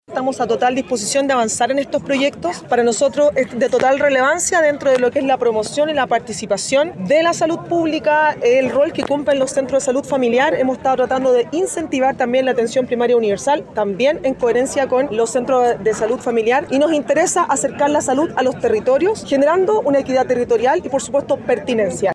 Por otro lado, la seremi de Salud de la región, Lorena Cofré, declaró que están a total disposición para avanzar en este tipo de proyectos.